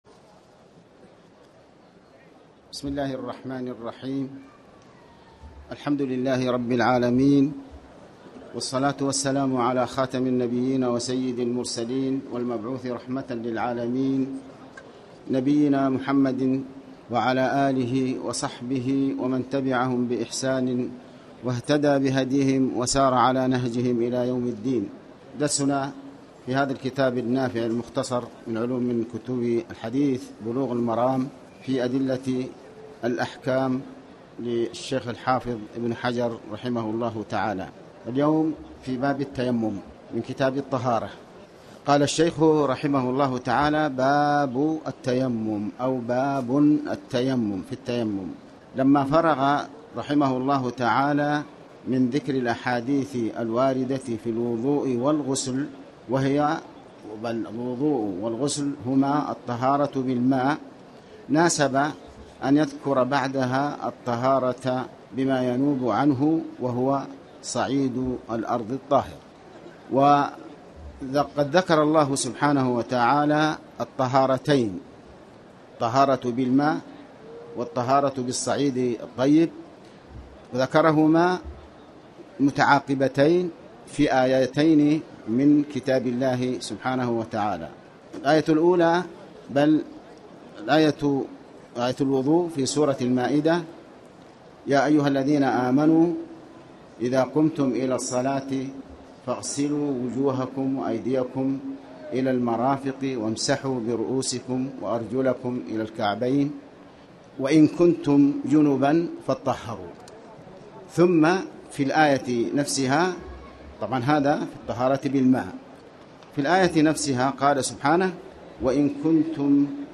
تاريخ النشر ٦ صفر ١٤٣٩ هـ المكان: المسجد الحرام الشيخ